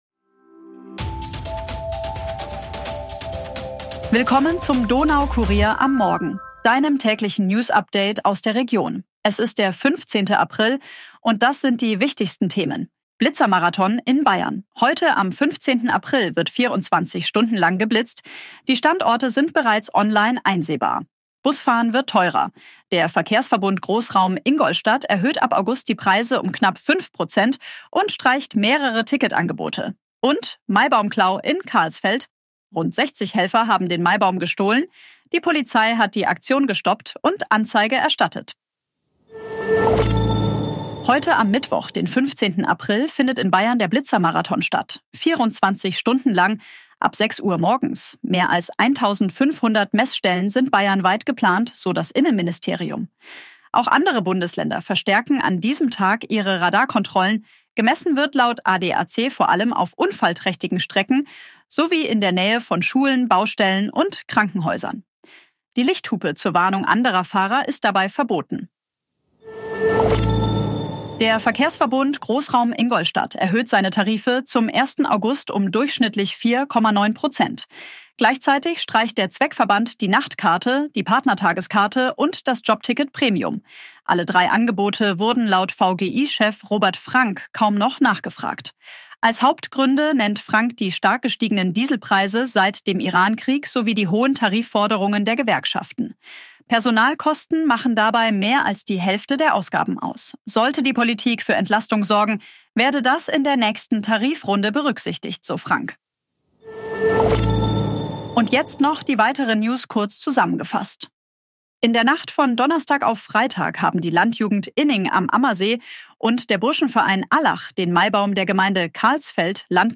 Tägliche Nachrichten aus deiner Region